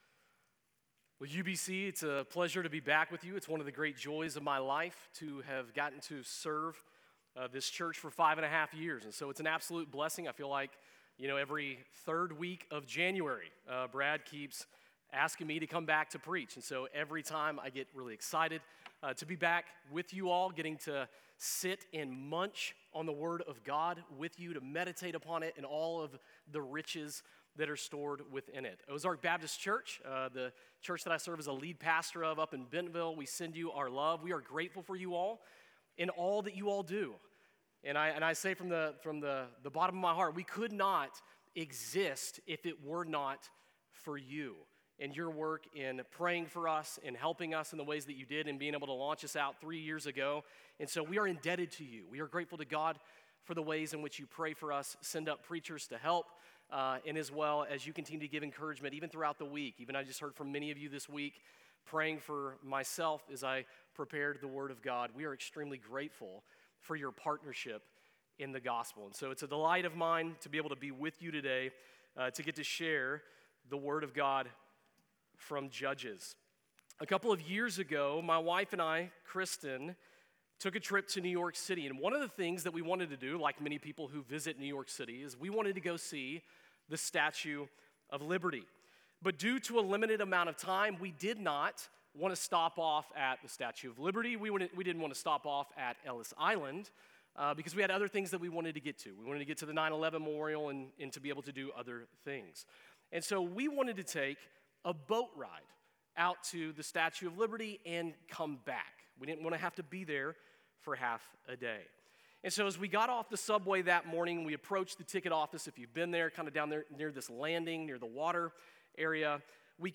Sermon Audio | University Baptist Church